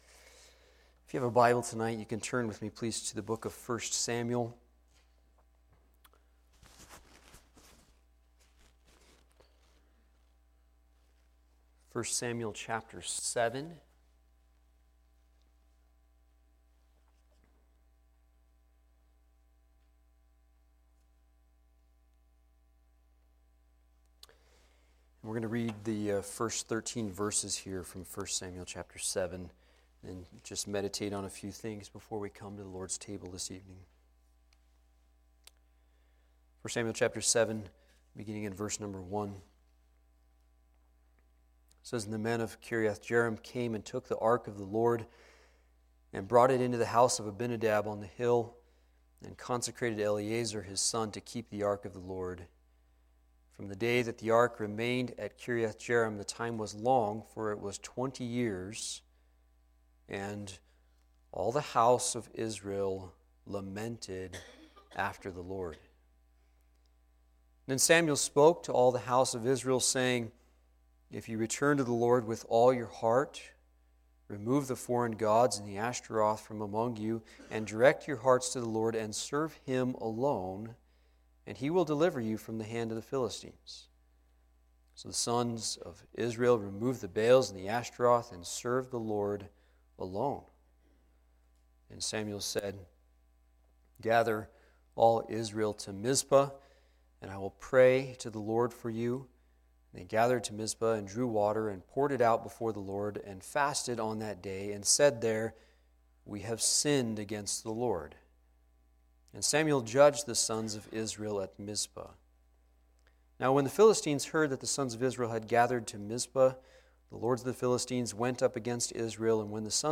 Evening Service